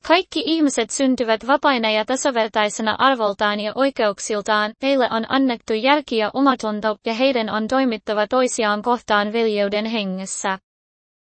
Custom AI voices generated in Finnish.
AI Voice in Finnish
Resemble Localize enables you to localize your American-English AI voice into Finnish with our advanced TTS engine.
finnish-tts.mp3